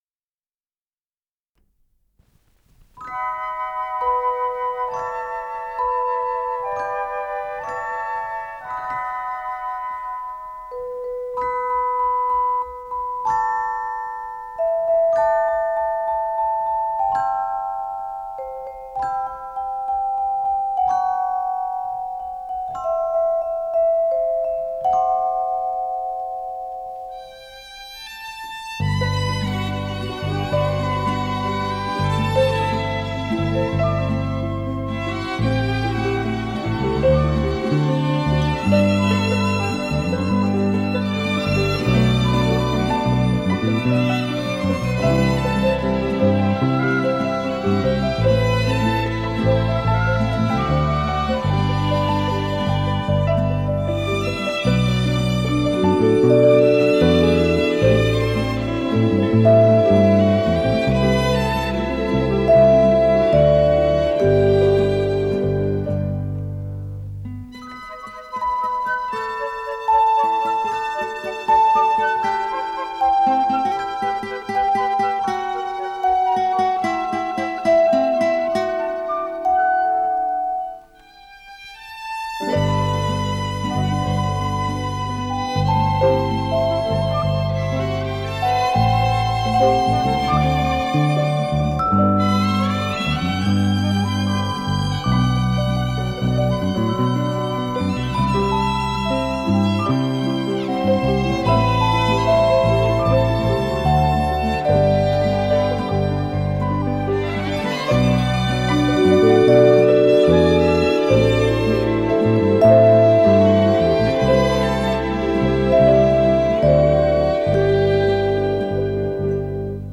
с профессиональной магнитной ленты
ПодзаголовокЗаставка
ВариантДубль моно